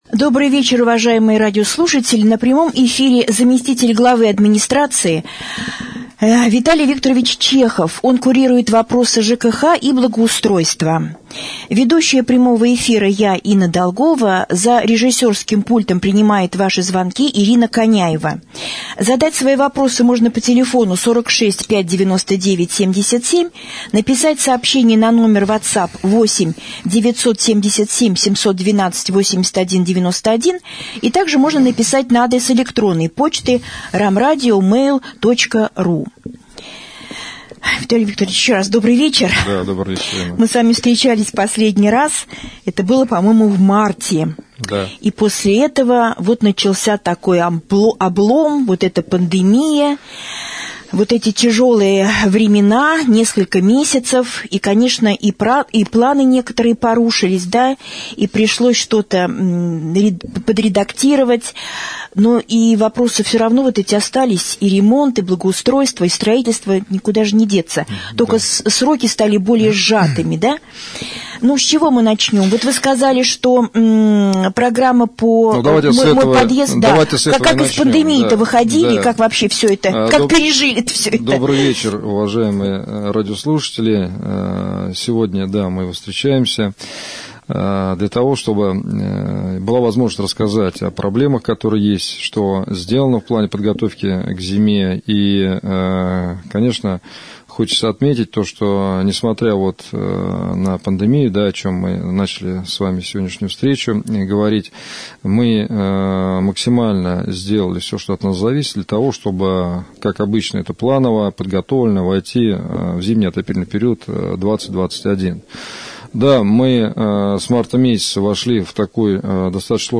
Гостем прямого эфира на Раменском радио 16 сентября стал заместитель главы администрации Раменского г.о.